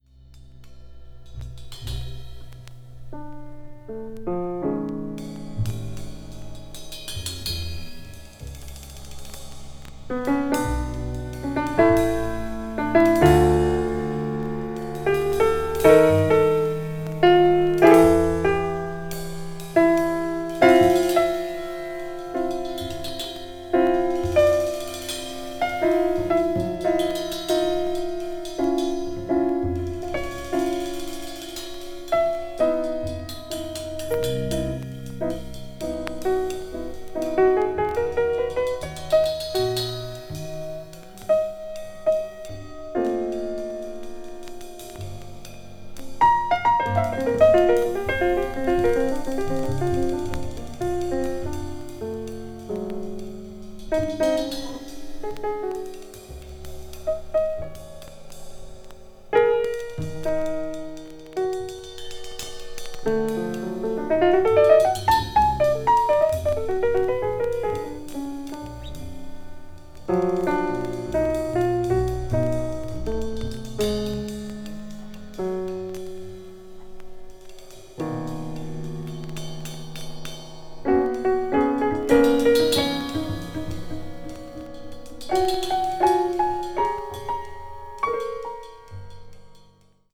抽象的な音の世界が出現しています